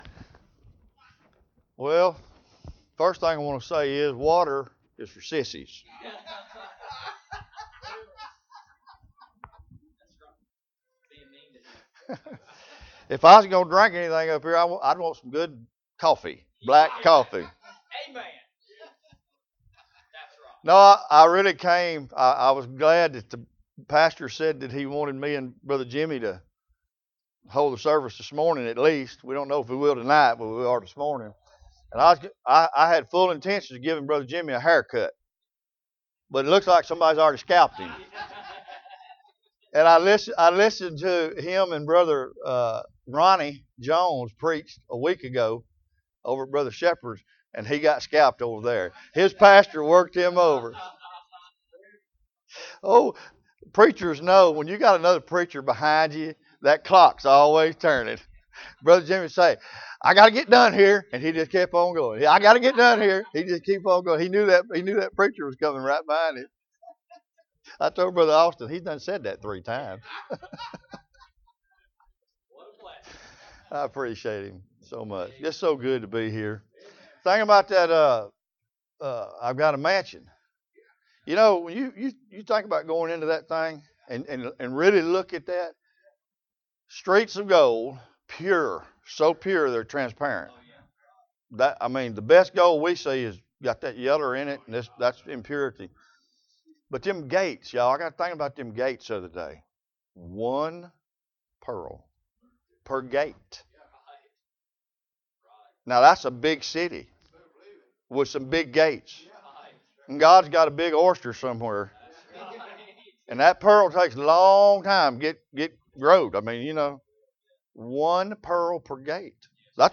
2021 Heavenly Places Preacher
2021 Bible Conference Passage: Ephesians Service Type: Bible Conference « Open Book Test What are you going to do with what you know?